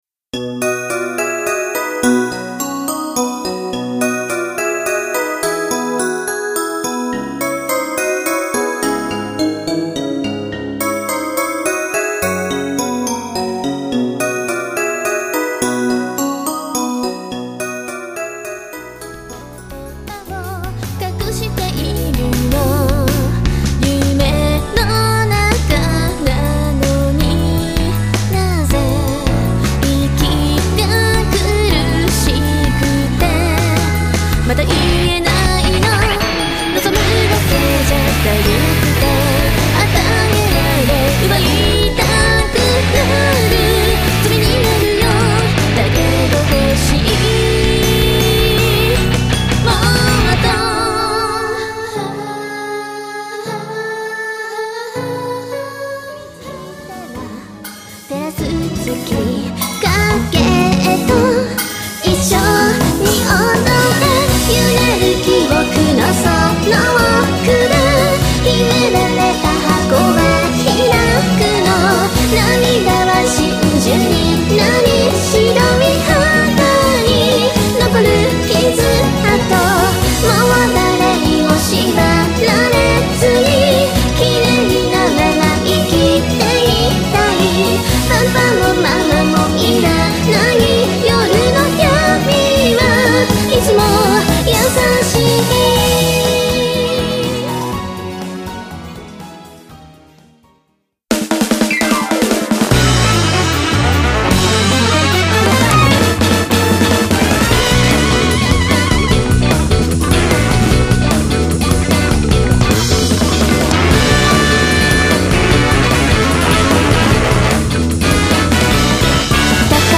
GUITAR
BASS